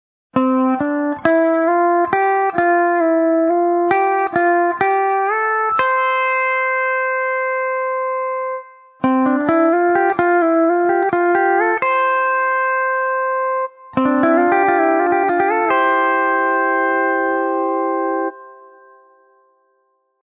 ペダルスチール　ドレミを弾く
ここでは、E9thチューニングでの弾き方について見てみます。
メジャースケールのフレーズ
ドレミを使った簡単な実用フレーズを紹介しておきましょう。